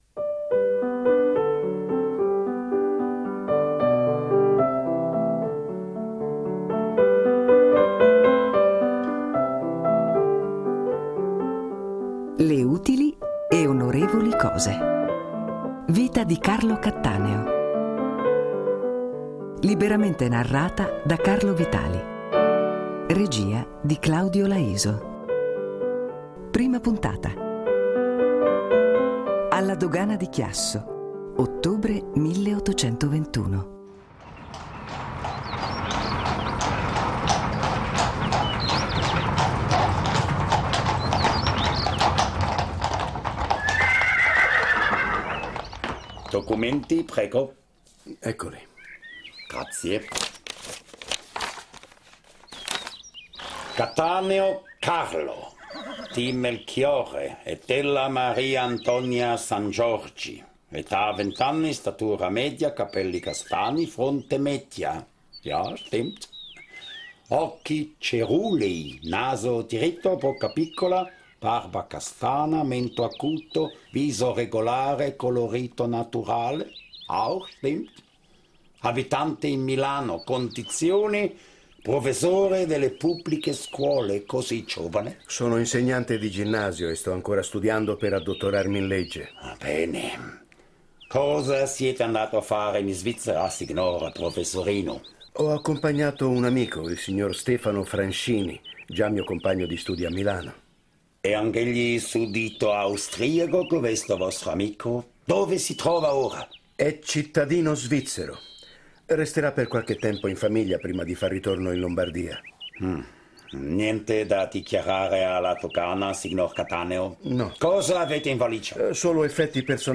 prosa radiofonica